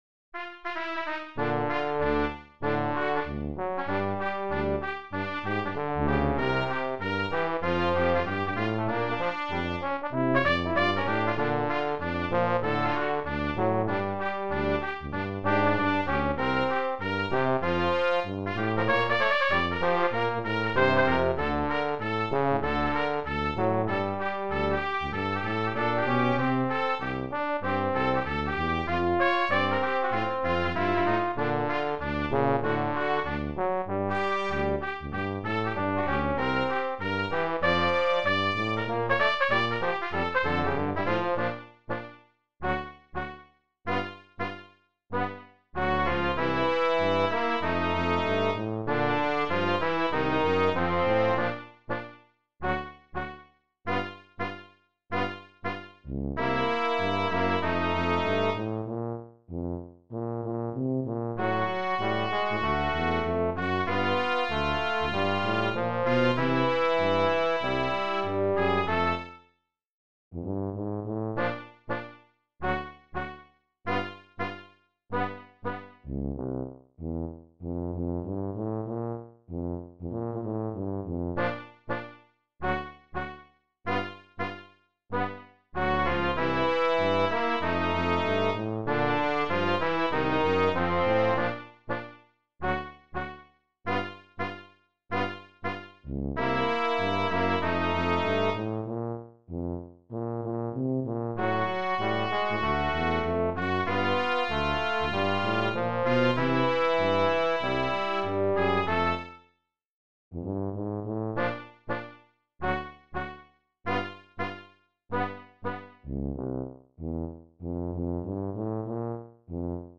Brass Trio TTT